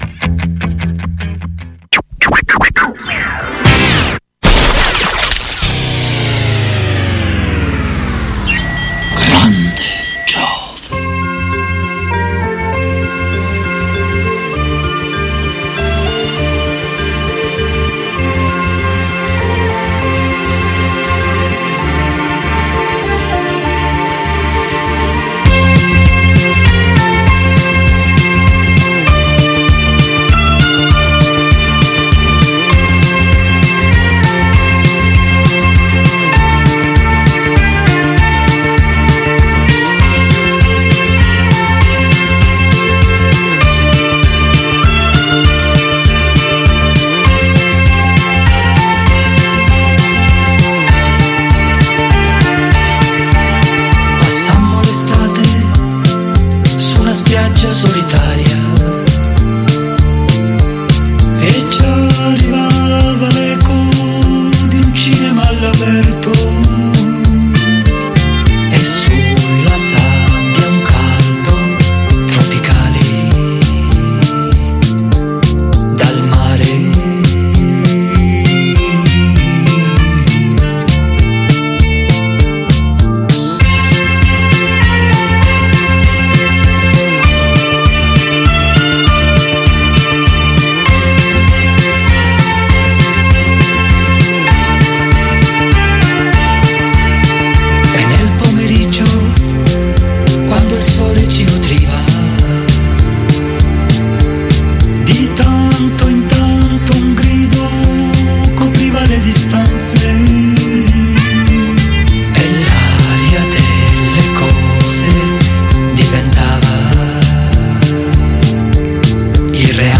Abbiamo selezionato anche musica insieme ai nostri ascoltatori che ci hanno fatto le loro richieste su whatsapp, facebook e telegram.